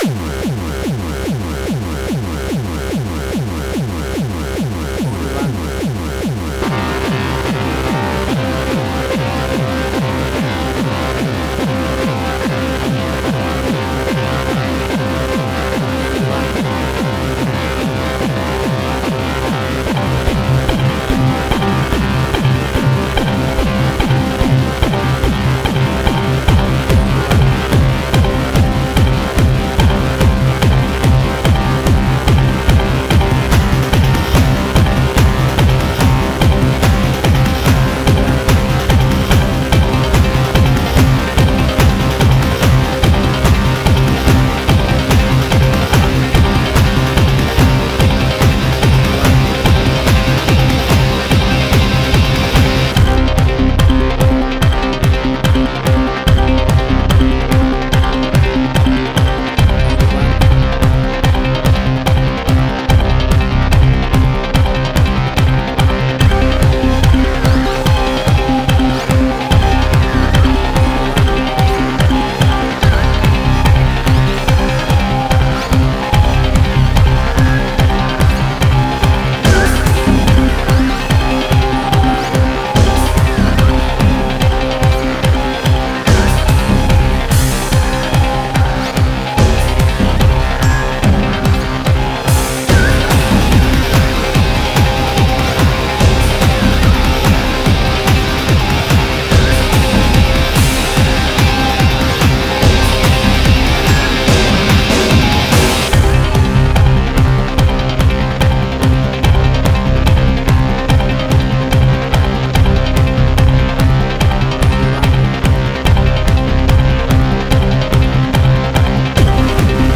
Cinematic Industrial Sci-fi.
Tempo (BPM): 144